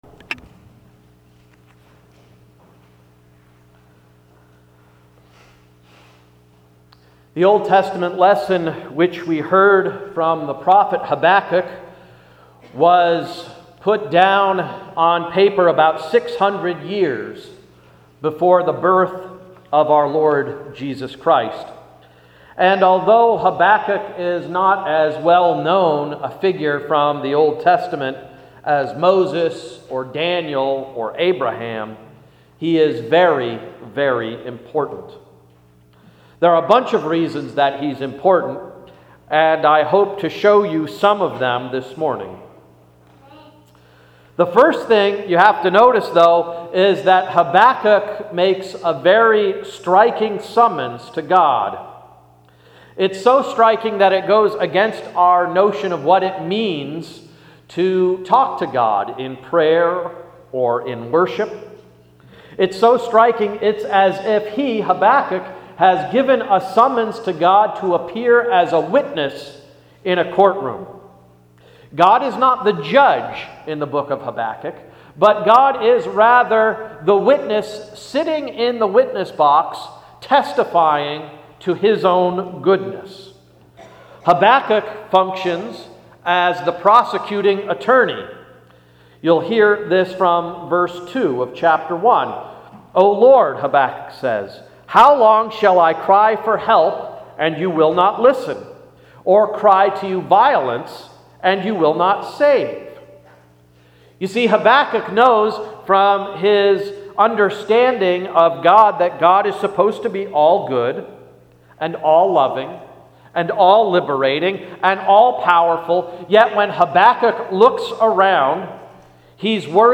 Sermon of October 3, 2010–“God in the Courtroom”